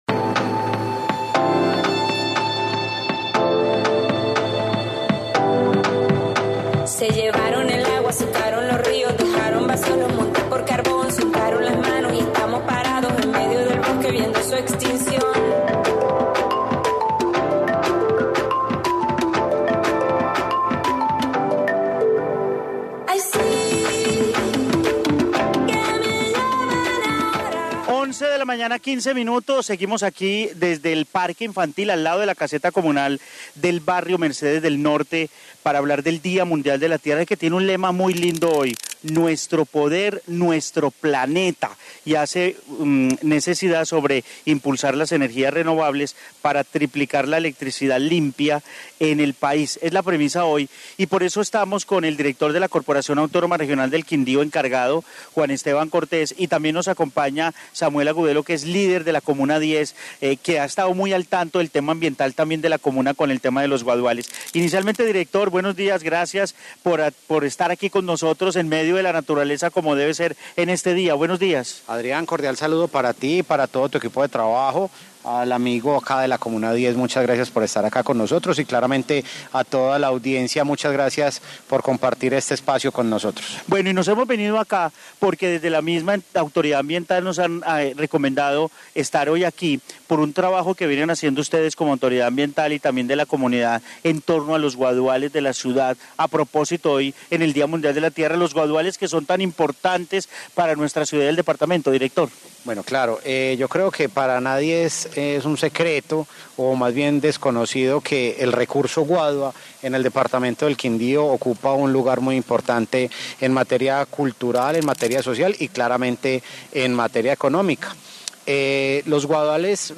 Informe Día de la Tierra con la CRQ